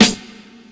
Snares
Grov_Sn.wav